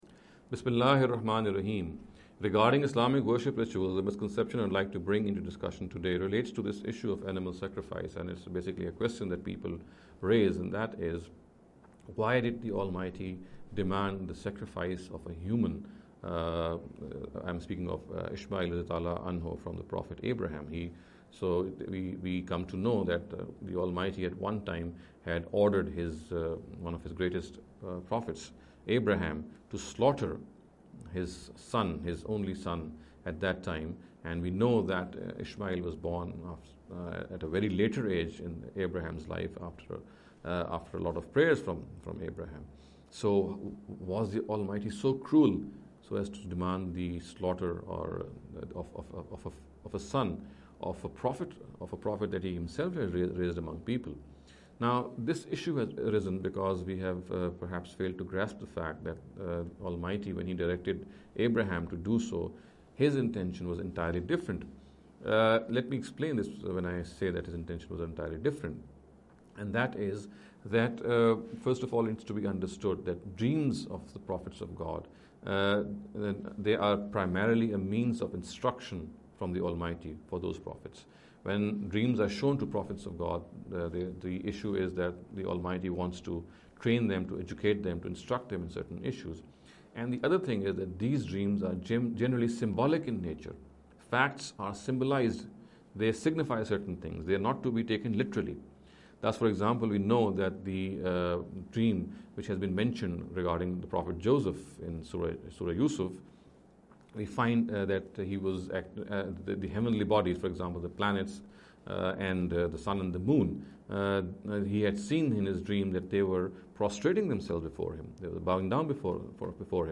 In every lecture he will be dealing with a question in a short and very concise manner. This sitting is an attempt to deal with the question 'Why did God ask Abraham (sws) to Sacrifice his Son?’.